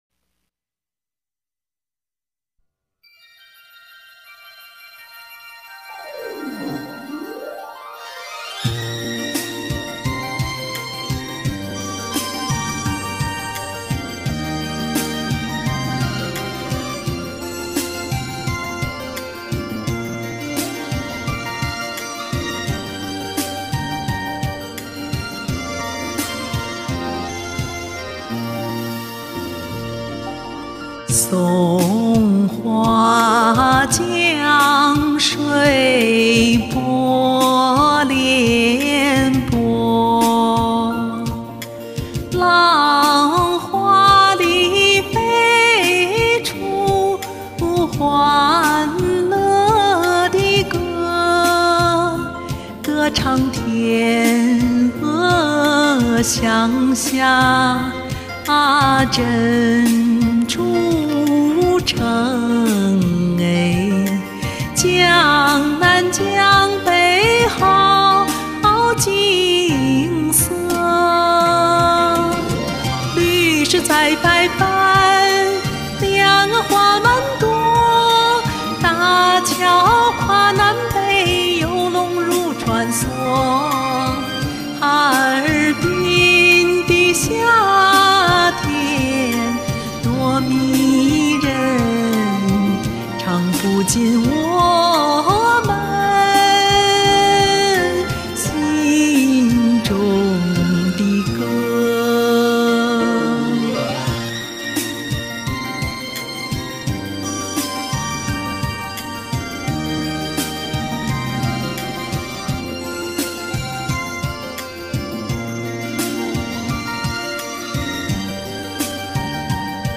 歌曲抒情，优雅动听，富有诗情画意。
声音清亮明澈真好听